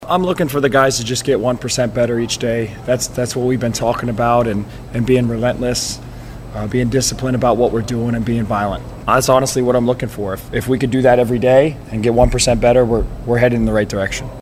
LINCOLN – Nebraska Football wrapped their final weekday practice of the second week of fall camp this morning, as a defensive emphasis was shown in the media pressers following the session.